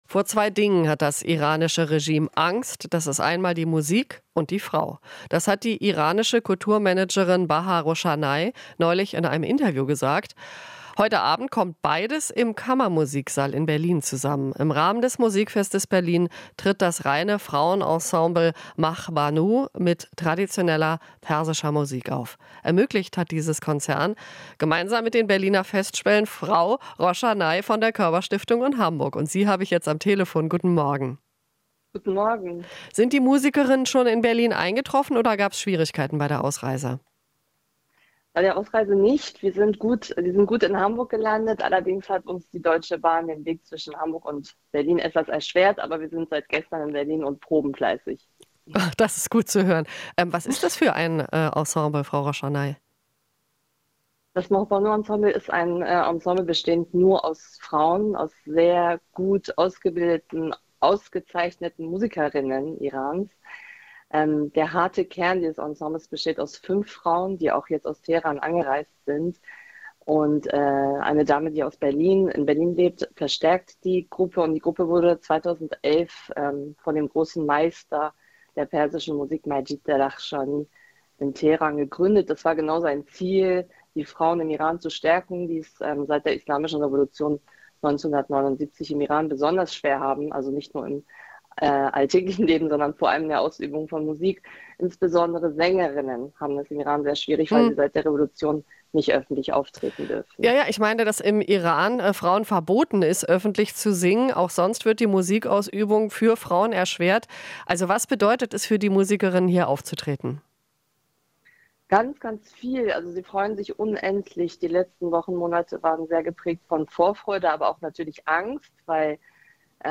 Interview - Iranische Musikerinnen beim Musikfest: Vorfreude und Angst